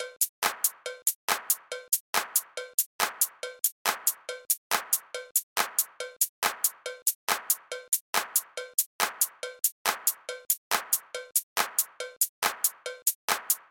Having been nosing around SoundFont files a suitable bell was not too hard to find.